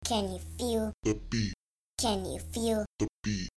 Tag: 115 bpm Electronic Loops Vocal Loops 304.13 KB wav Key : Unknown